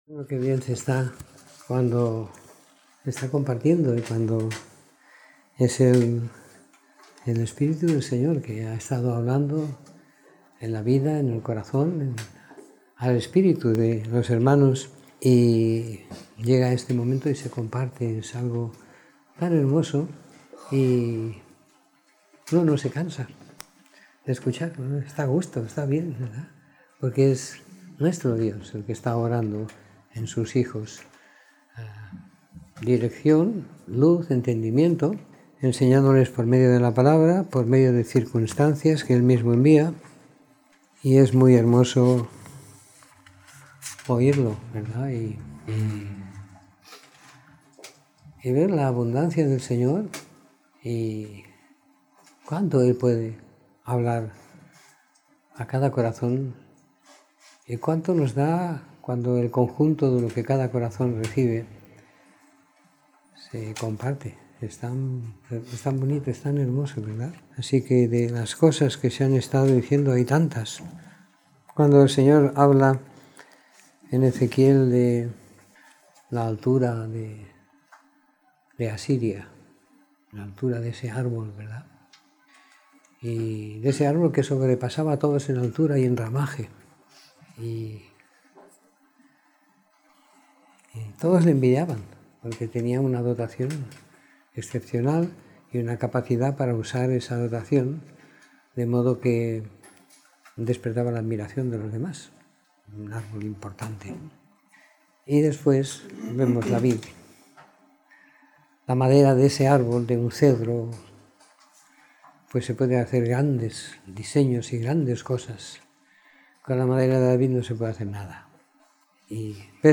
Reunión de Domingo por la Tarde